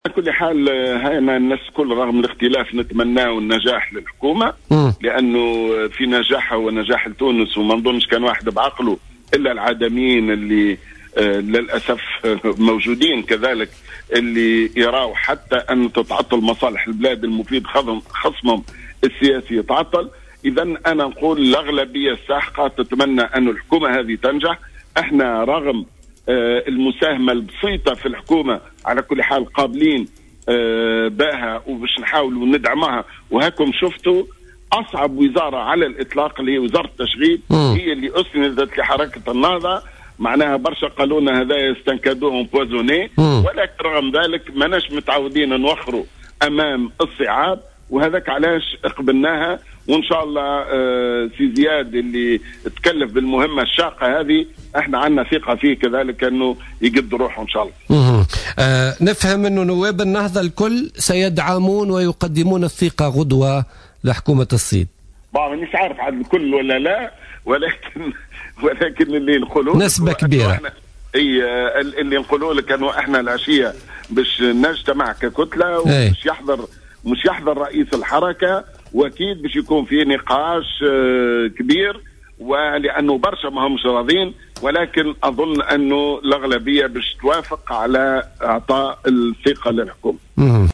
رجّح القيادي في حركة النهضة،محمد بن سالم ضيف "بوليتيكا" اليوم أن يصوّت أغلبية نواب النهضة لصالح حكومة الحبيب الصيد الجديدة.